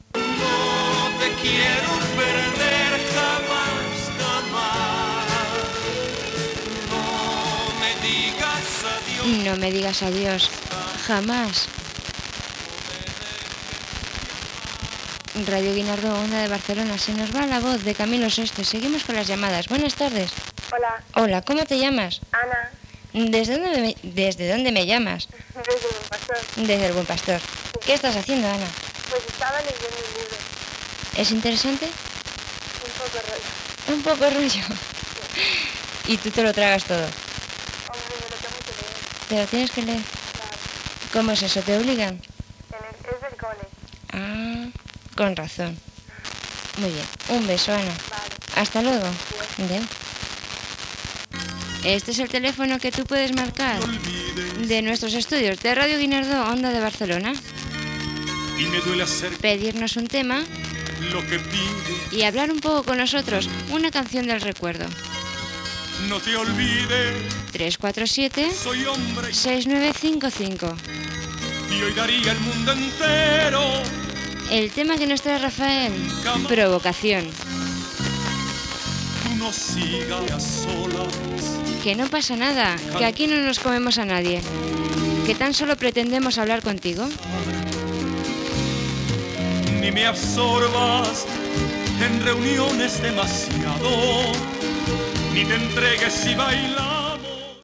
telèfon, identificació i tema musical.